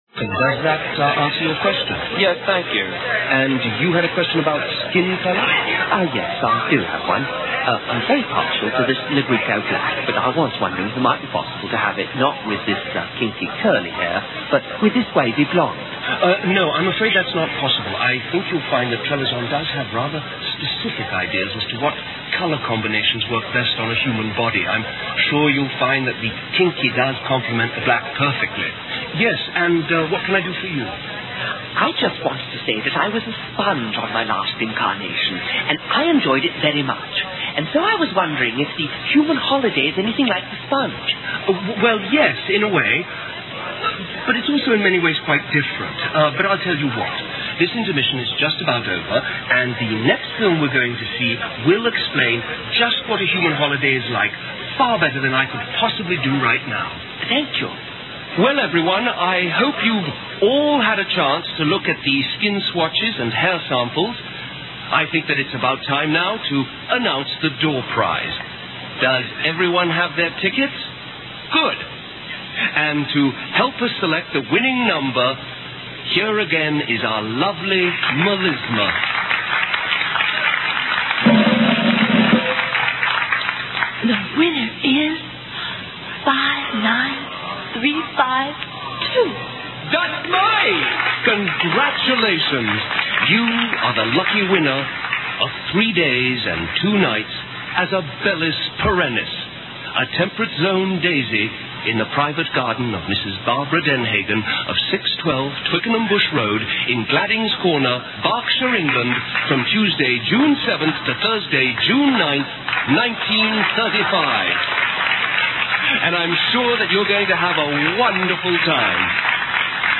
Fun Filled British Radio Play - mp3 - Part 2